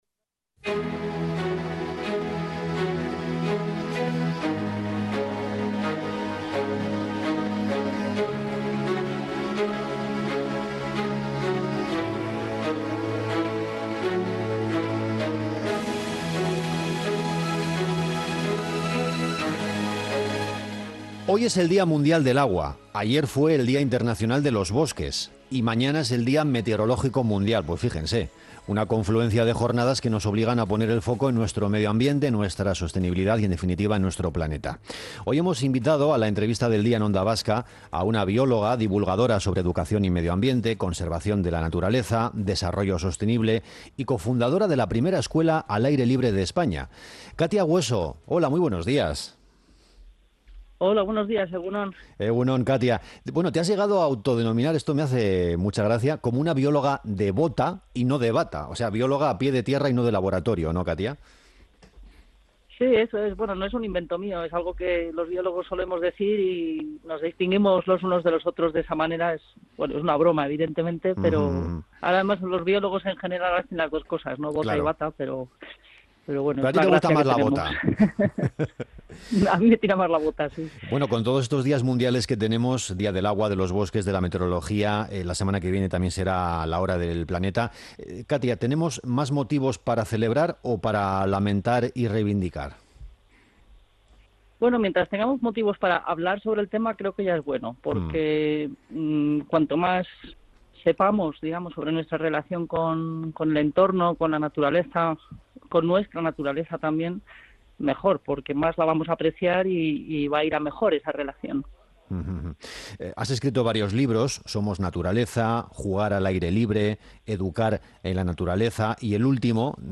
Sobre esto hemos hablado con la bióloga